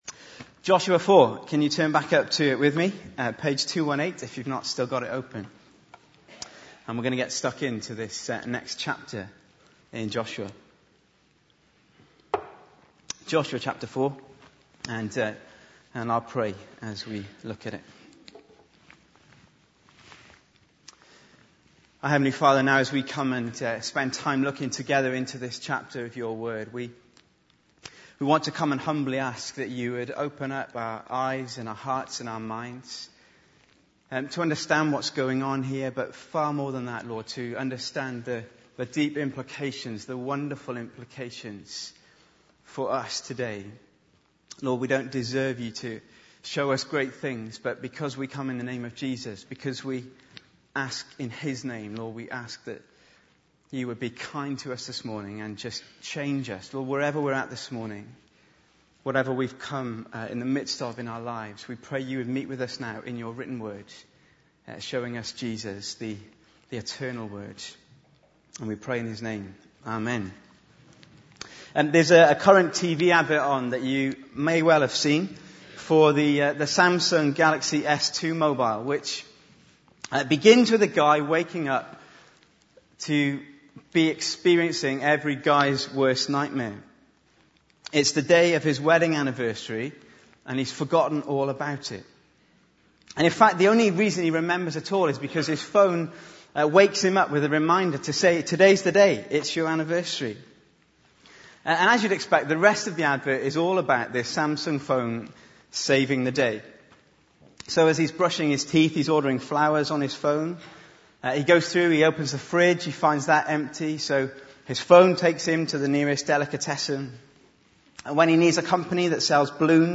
Bible Text: Joshua 4:1-24 | Preacher